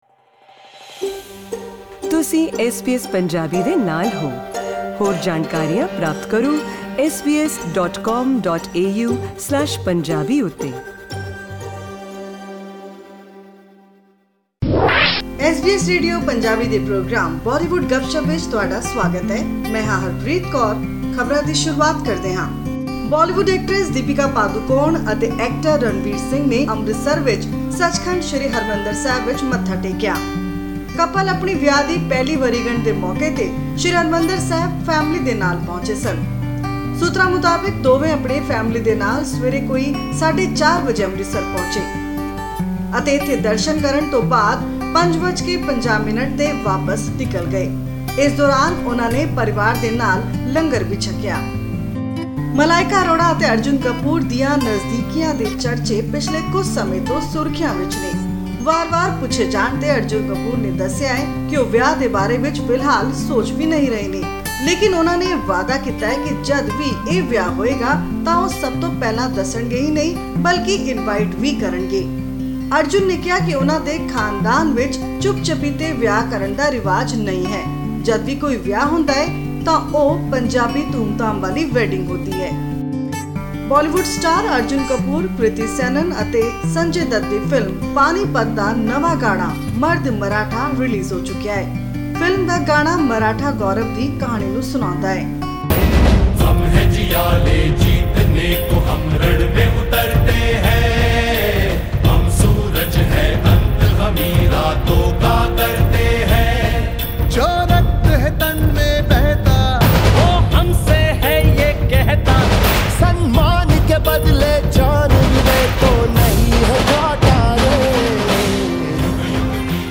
Listen to this week's Bollywood Gupshup for all the latest filmi news and clips of best songs.